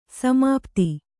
♪ samāpti